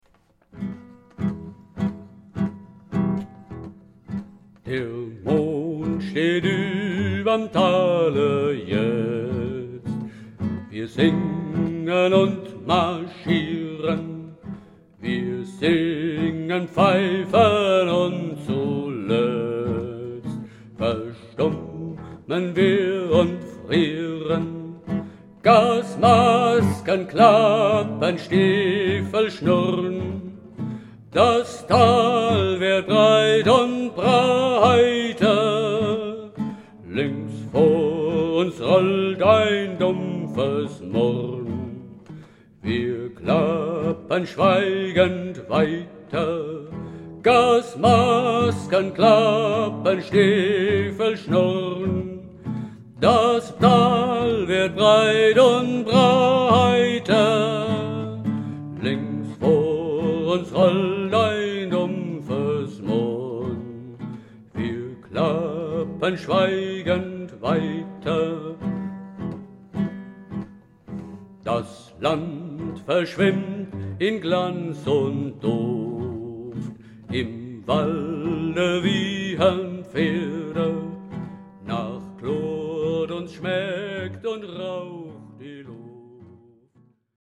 e-Moll